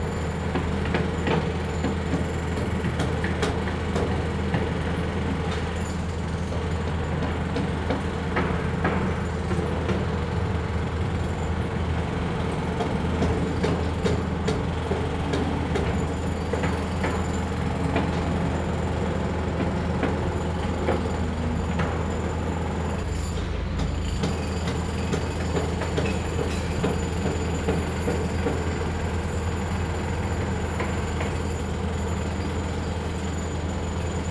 background_construction_building_loop.wav